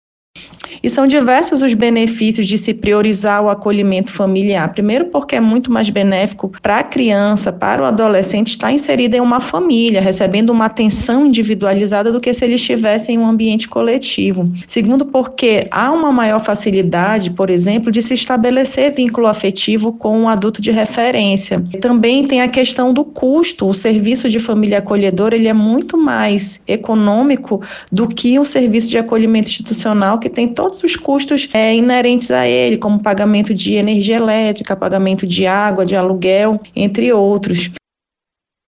O objetivo é dar efetividade ao Estatuto da Criança e do Adolescente (ECA), que estabelece que o acolhimento familiar deve ser prioritário em relação ao acolhimento institucional, como explica a promotora Ynna Breves Maia Veloso.